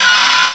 cry_not_venipede.aif